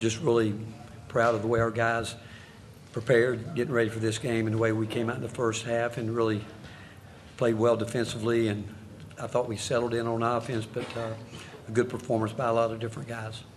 Tennessee head coach Rick Barnes gave praise to his players for how they attacked in the first half, and especially to the defense for holding the large lead.